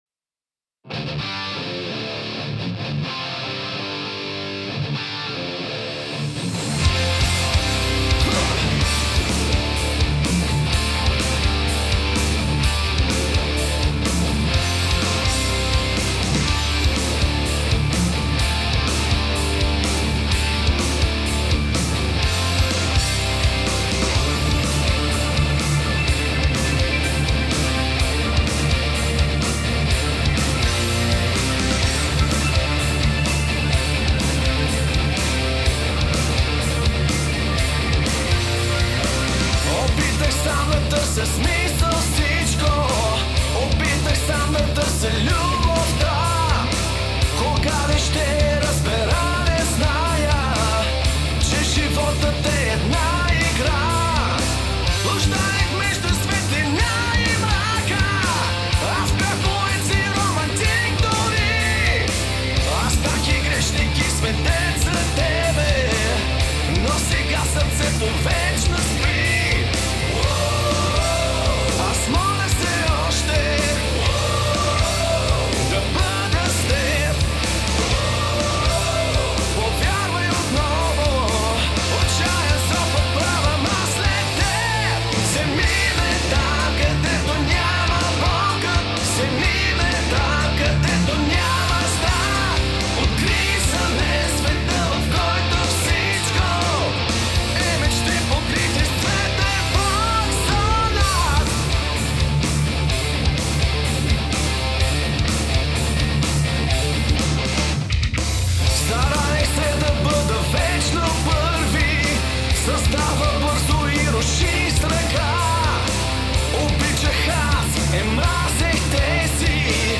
В специално интервю за нашето предаване той разказва за процеса по създаването на песента, символиката и посланията които стоят зад текста, както и за чакащите ред за издаване нови песни на групата.